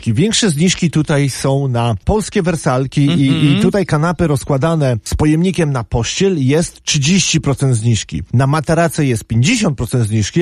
W studiu Radia Deon właściciel sklepu